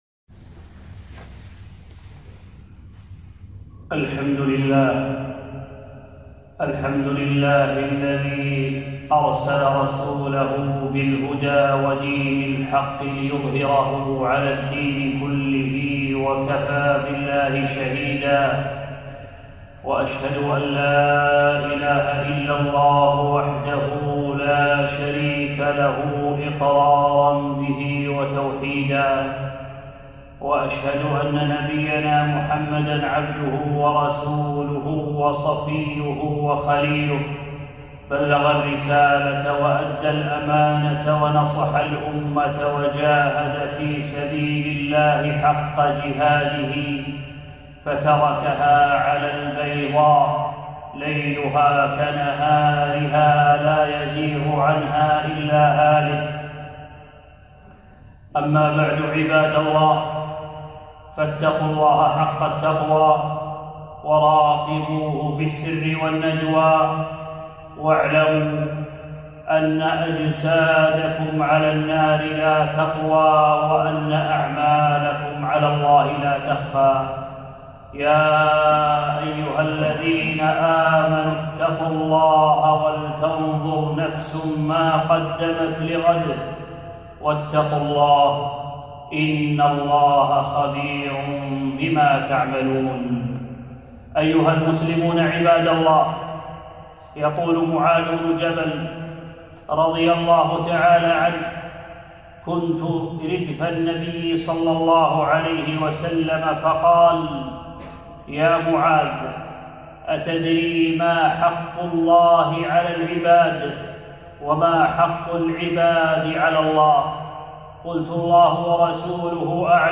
خطبة - المحافظة على التوحيد